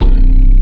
61BASS01.wav